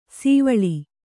♪ sīvaḷi